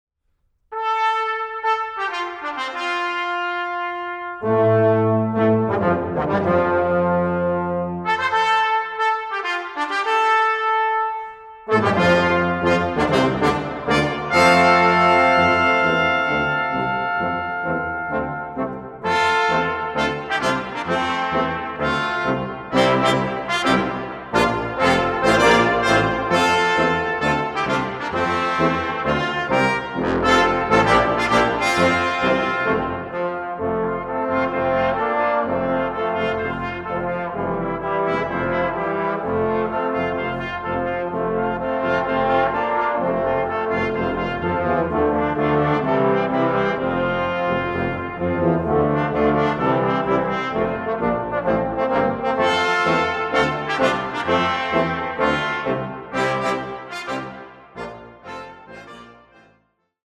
Fantasia for brass ensemble